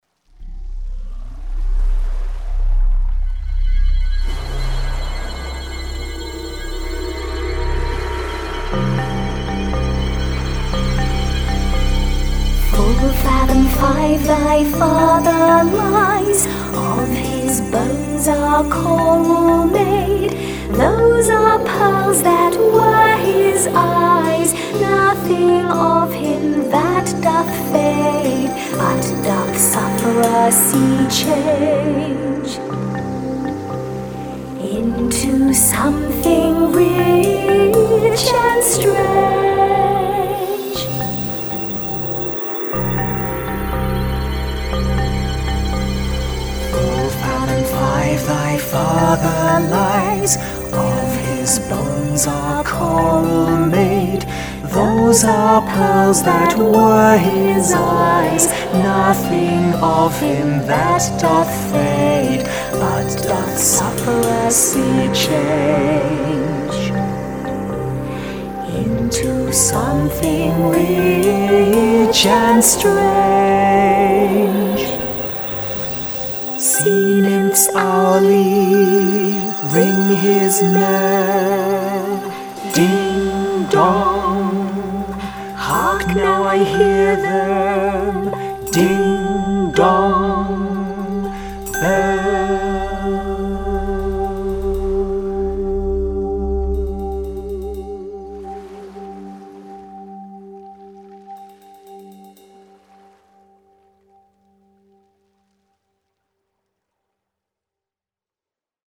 gentle, ethereal and haunting style. Full vocal.